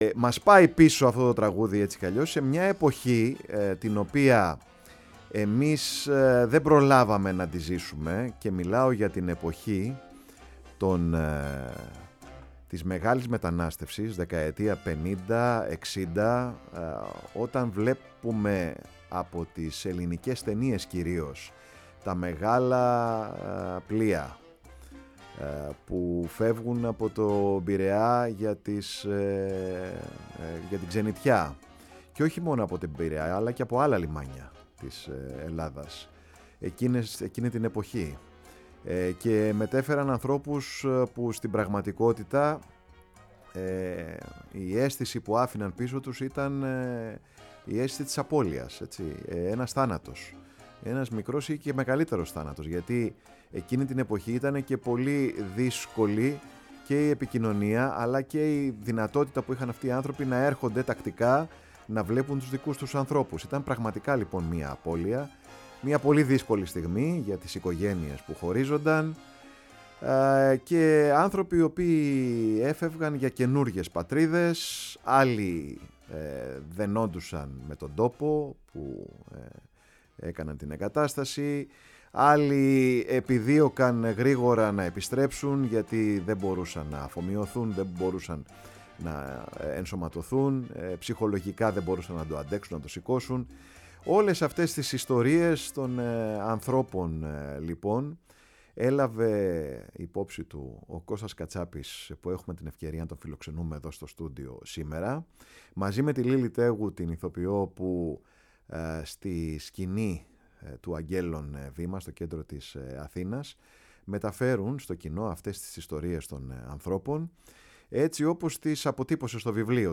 φιλοξένησε στο στούντιο